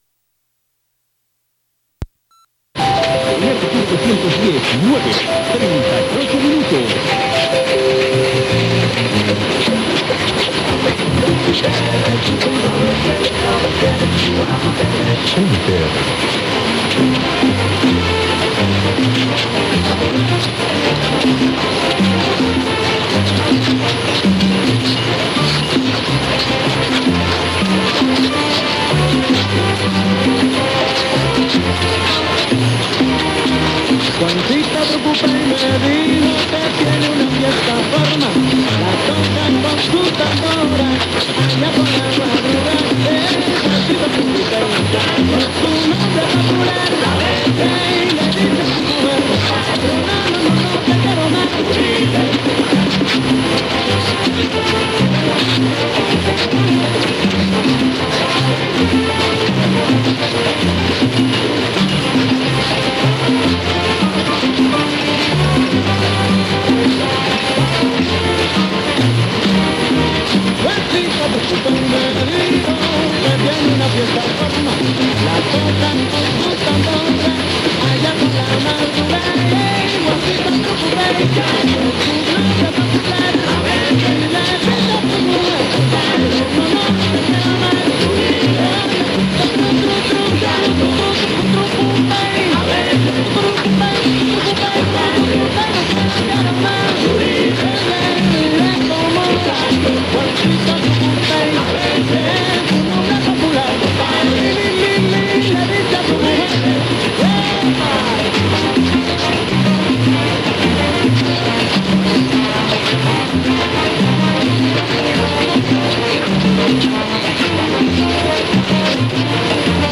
yvrq_c-quam_stereo_with_salsa_trop_mx.mp3